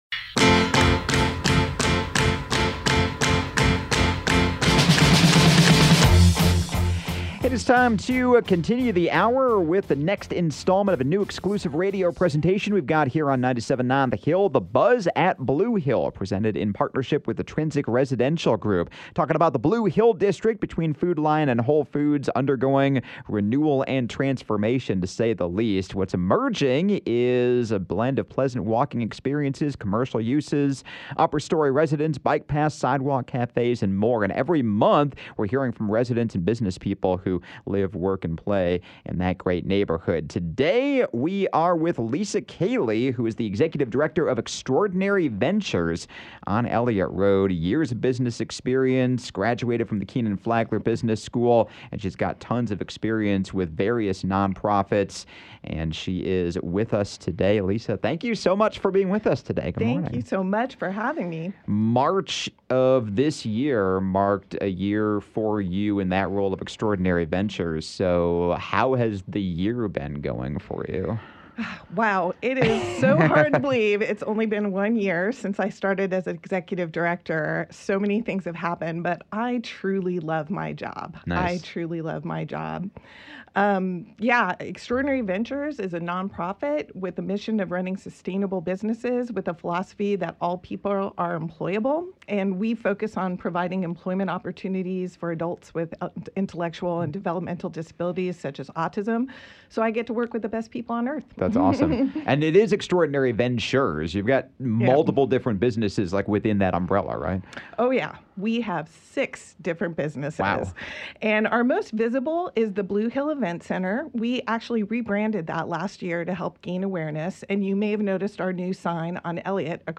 “The Buzz at Blue Hill” is an exclusive radio presentation in partnership with Trinsic Residential Group on 97.9 The Hill.